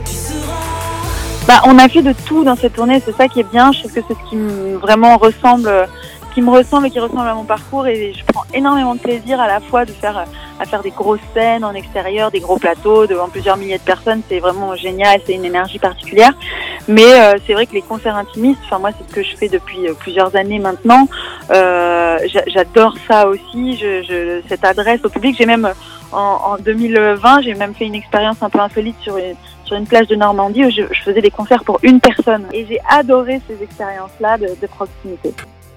Emma DAUMAS se confie sur NRJ